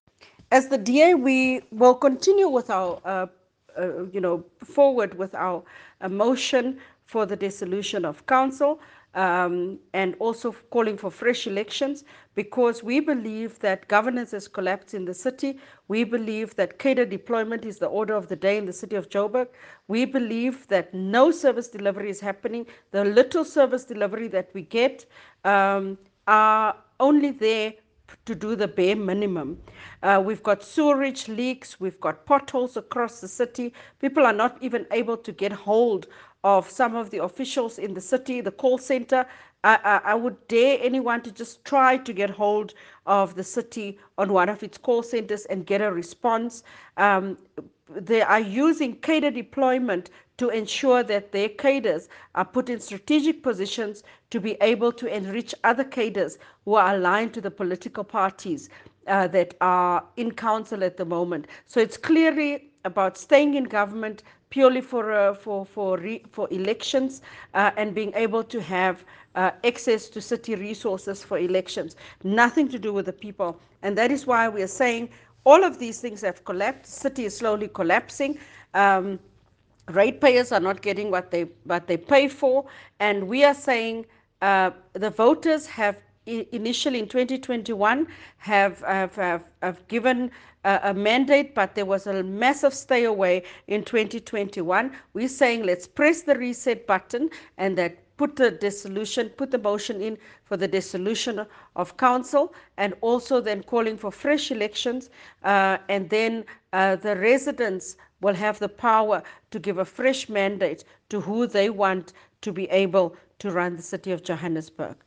Issued by Cllr Belinda Kayser-Echeozonjoku – DA Johannesburg Caucus Leader
Note to editors: Please find a soundbite in English